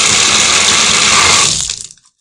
浴缸挡水板
描述：在带有Android平板电脑的浴室中录制，并使用Audacity进行编辑。